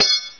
swipehitw.wav